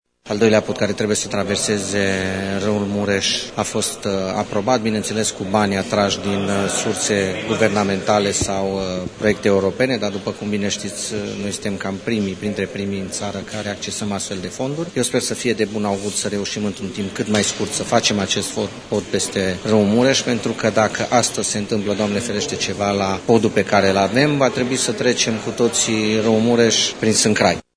Viceprimarul Claudiu Maior spune că investiţia va fi realizată prin atragerea de fonduri europene: